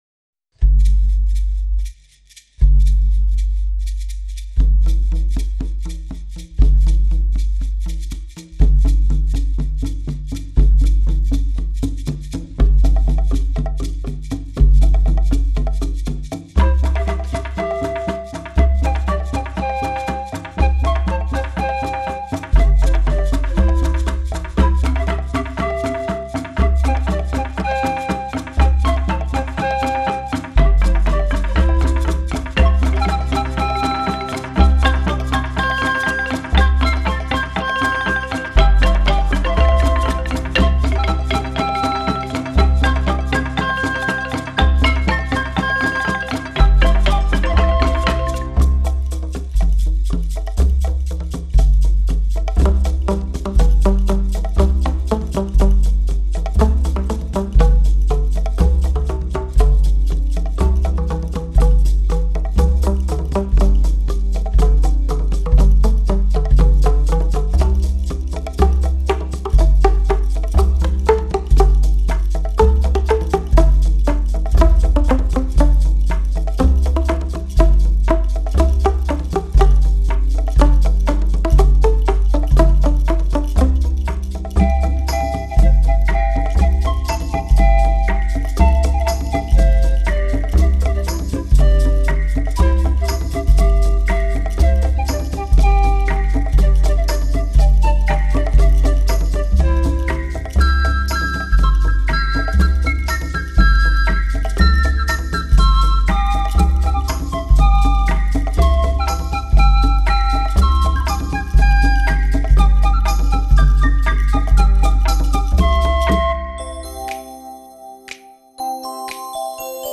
迥异的经典民歌素材，在全新的声音组合中，无不以温润淡雅、余音袅袅的竹声弥散开来。
本片使用了二十余种竹制乐器，形制和种类都更为丰富，相、竹炭、炭管琴、竹排筒、